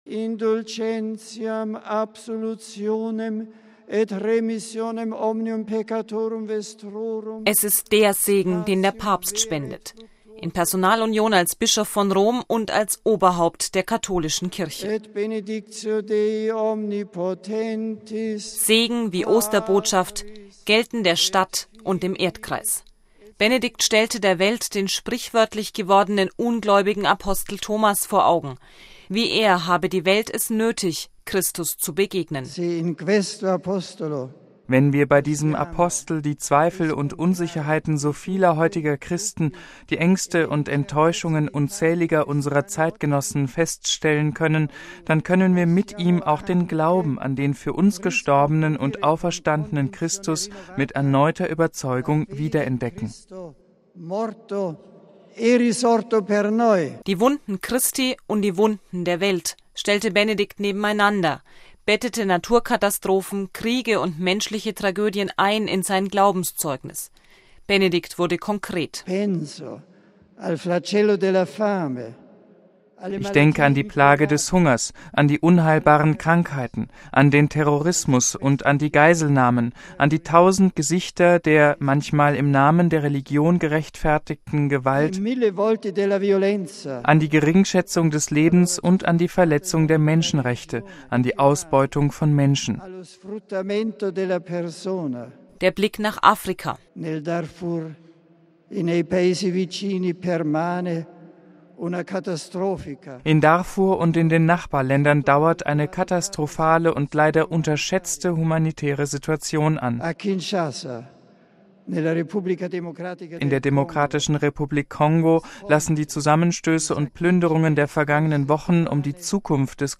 Die Osterbotschaft des Papstes stand am Ende des Gottesdienstes am Ostermorgen.
Ein russischer Chor sang den Osterhymnus des byzantinischen Ritus.
Das Bekenntnis ging auf dem Petersplatz direkt über in den lateinischen Gesang des Credo.